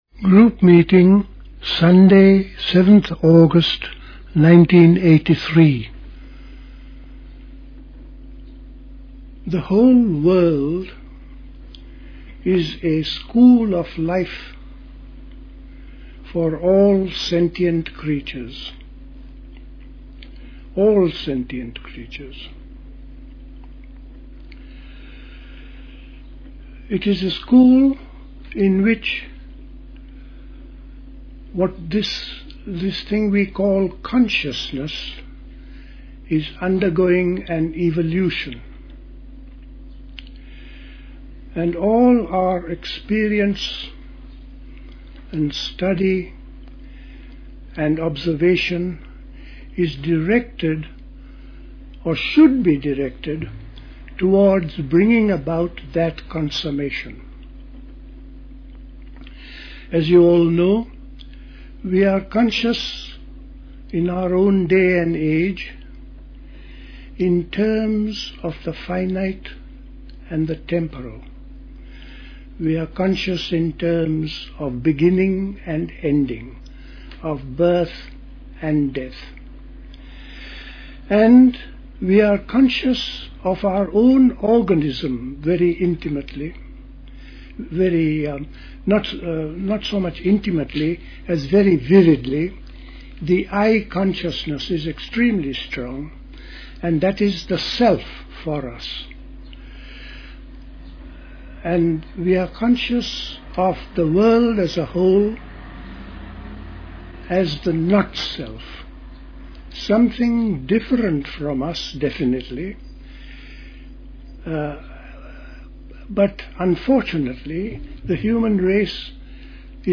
A talk
at Dilkusha, Forest Hill, London on 7th August 1983